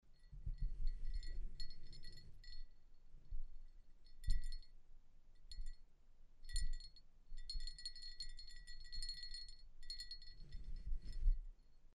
Sound recordings of the original pellet bells and bells from the Avar period kept in the collection of the Great Migration Period of the Hungarian national Museum Budapest.
Sound of original pellet bell Kiskőrös-Vágóhídi-dűlő grave 30 0.19 MB
Pellet_bell_Kiskoeroes_Vagohidi_dueloe_grave_30.MP3